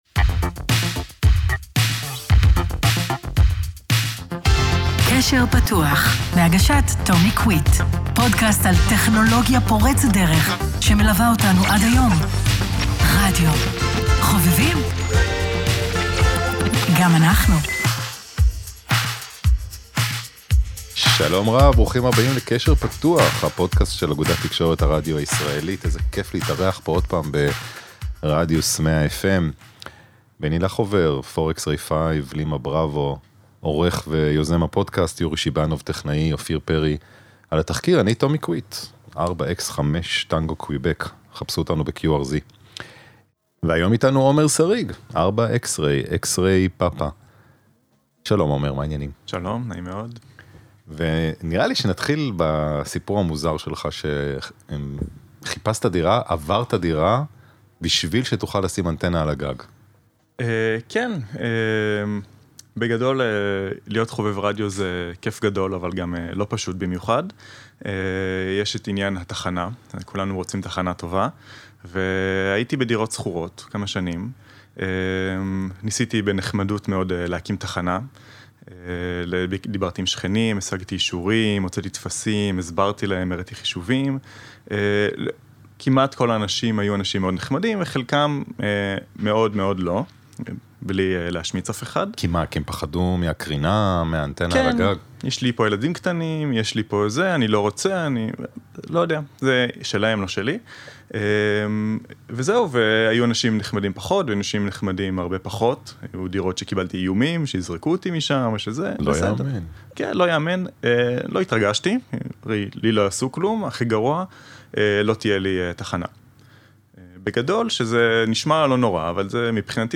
הוקלט באולפני רדיוס 100FM